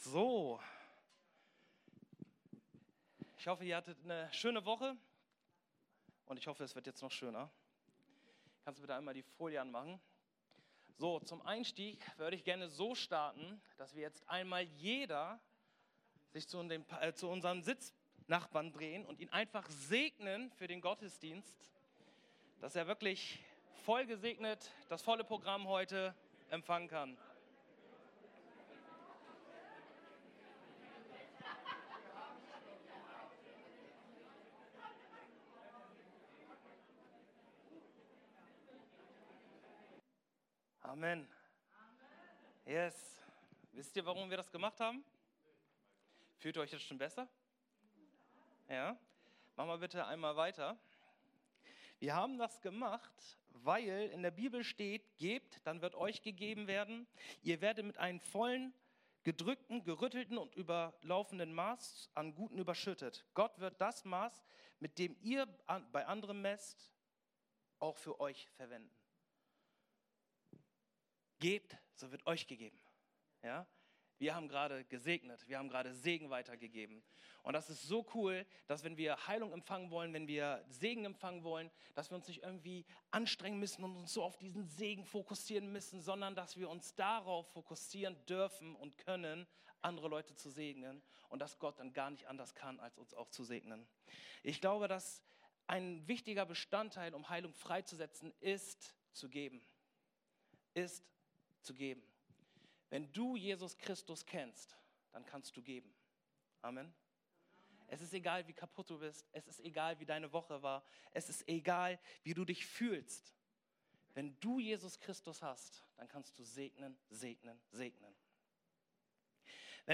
Predigten – OASIS Kirche